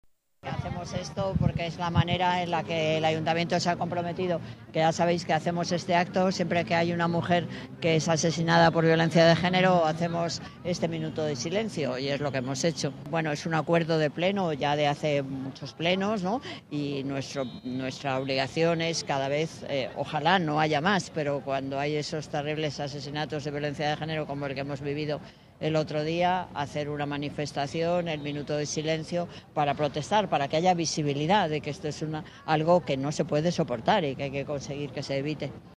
Convocado por el Ayuntamiento de Madrid para este mediodía en las puertas de acceso a todas las dependencias municipales
Nueva ventana:Declaraciones de Manuela Carmena durante el minuto de silencio